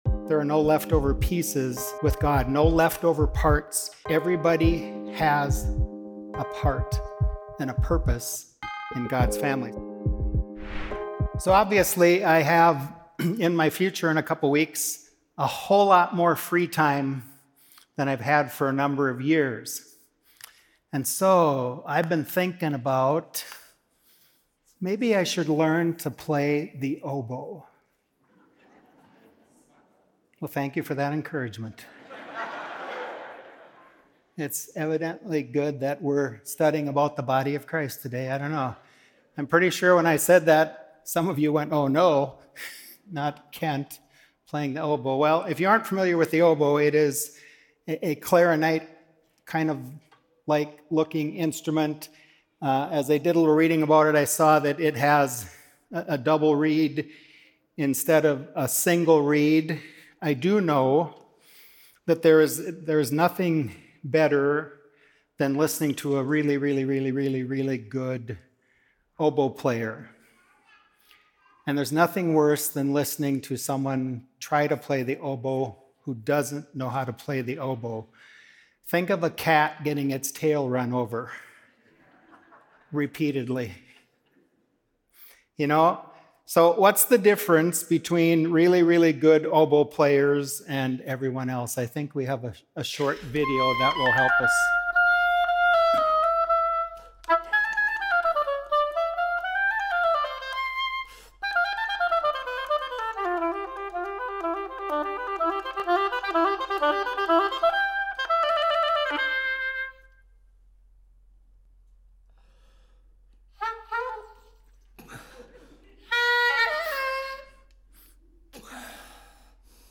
A message from the series "The Church."